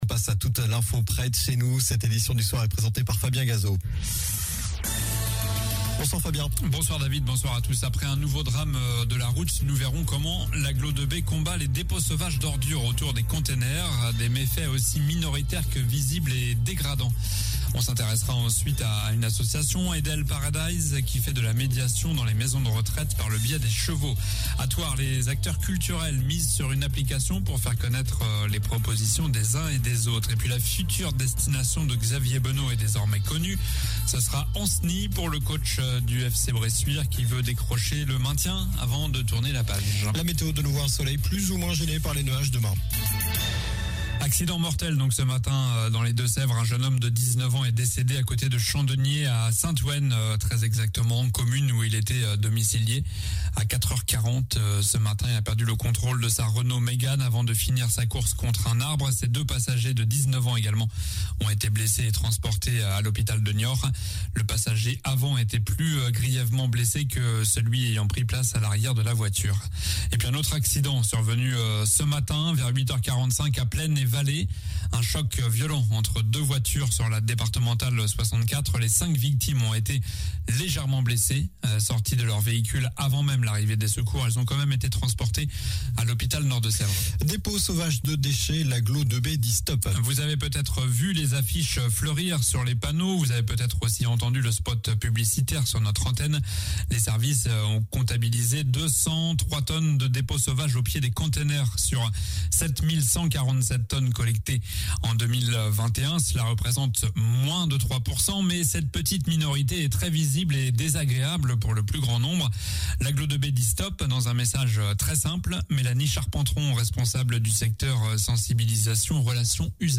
Journal du mercredi 20 avril (soir)